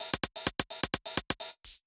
Index of /musicradar/ultimate-hihat-samples/128bpm
UHH_ElectroHatA_128-02.wav